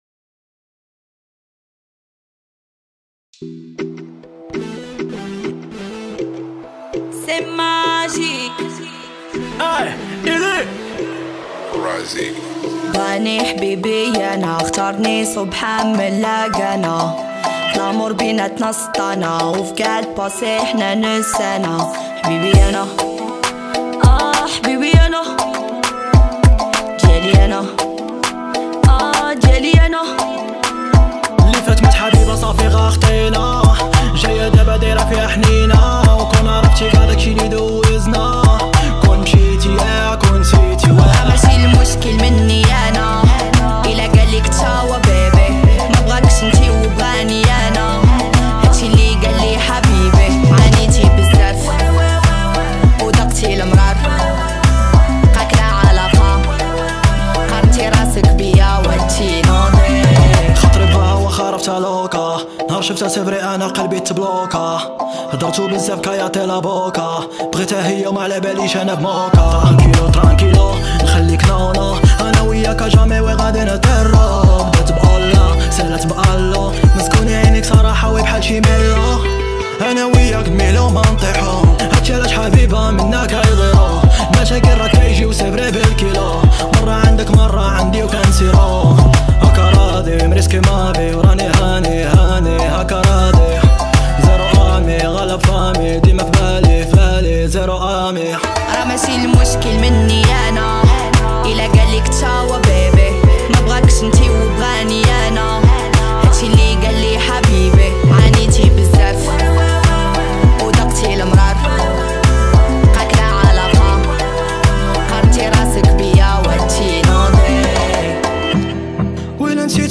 اغاني الراب استماع songالنسخة الأصلية MP3